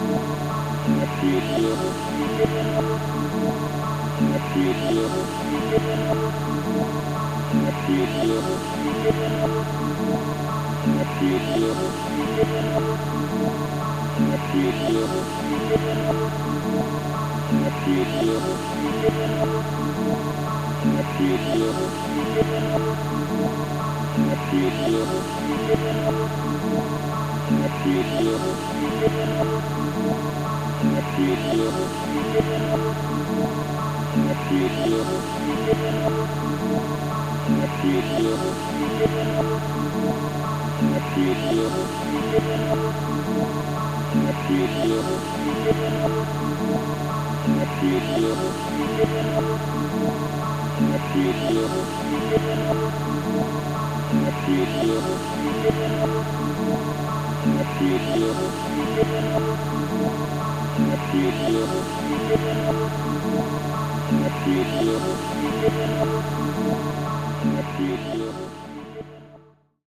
Speed 150%